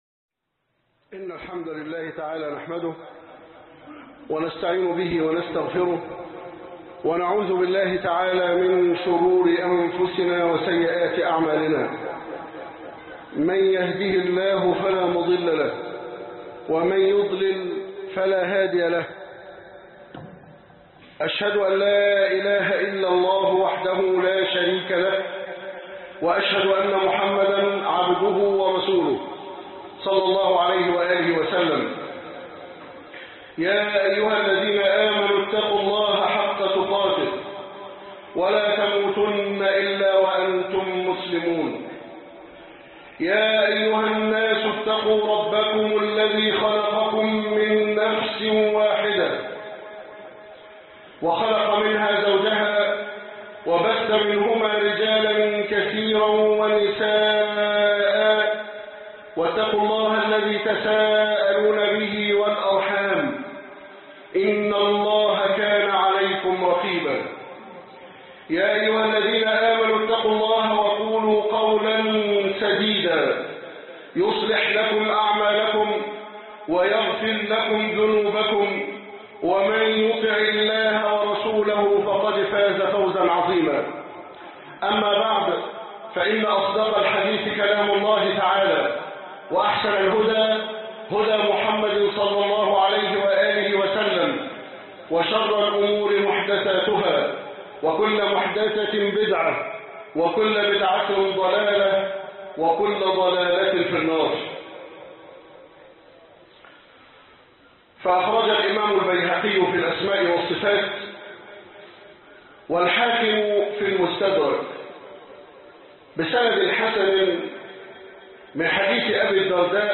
ثلاثة يحبهم الله عزوجل - خطب الجمعة